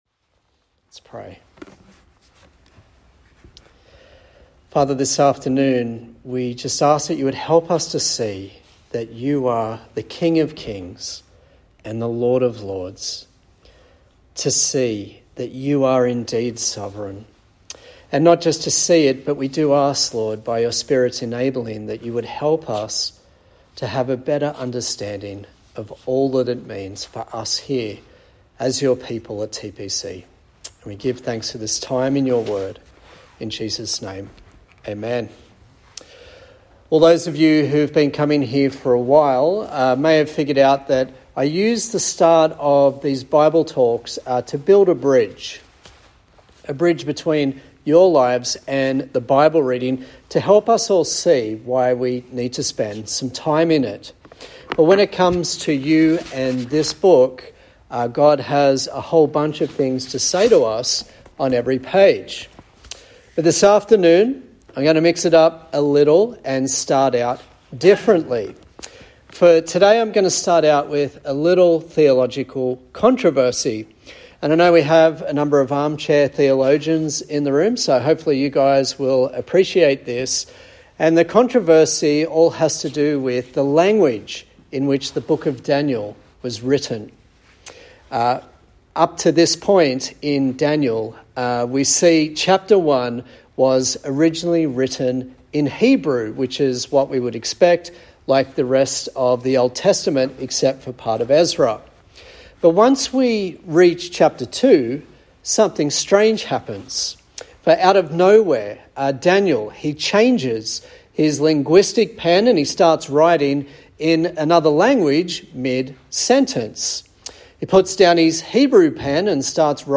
Daniel Passage: Daniel 2 Service Type: Sunday Service